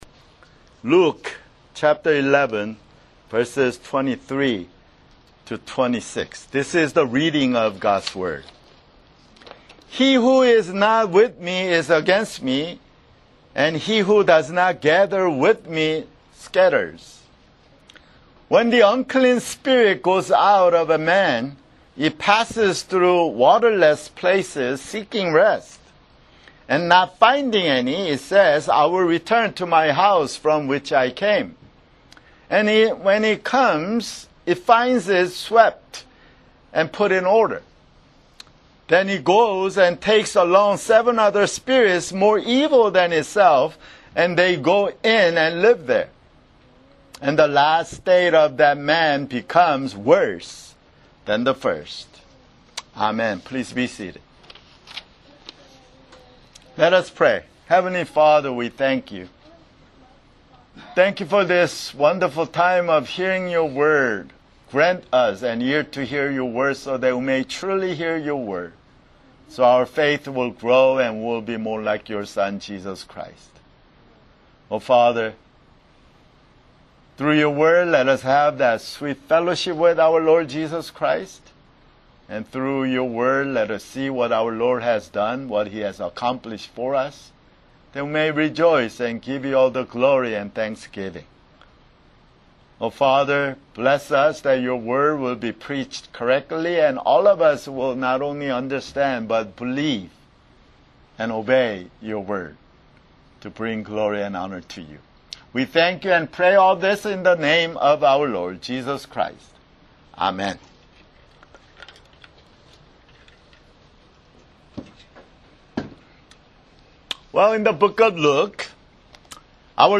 [Sermon] Luke (82)